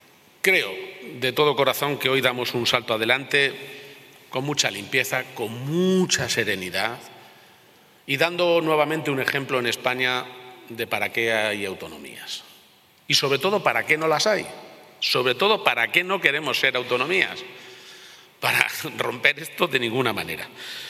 Presidente Jueves, 29 Mayo 2025 - 2:45pm El presidente regional ha asegurado que hoy damos un salto adelante con mucha limpieza, con mucha serenidad y dando nuevamente un ejemplo en España de para qué hay autonomías y, sobre todo, para qué no las hay y para qué no queremos ser autonomías: para romper esto de ninguna manera. presidente.clm_dando.ejemplo_290525.mp3 Descargar: Descargar